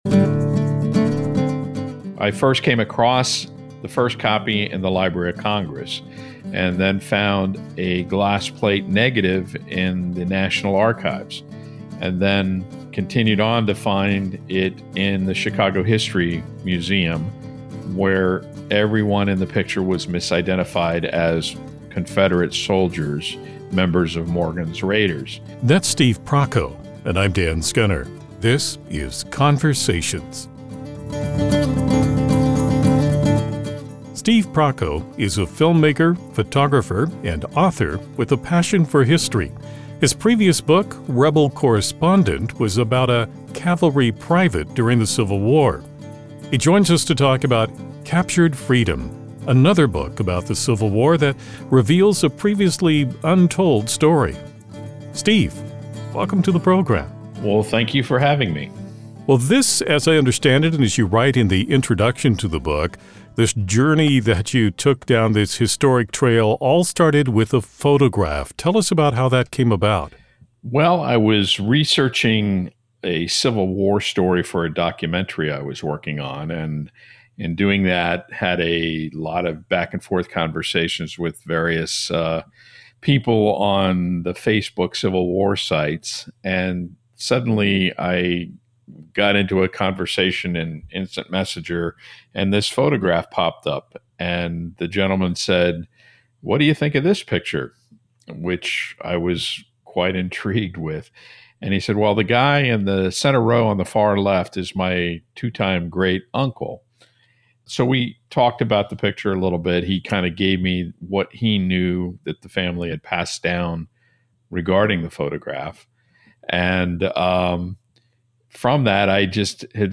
Interview with Kansas Public Radio Captured Freedom - An epic Civil War escape story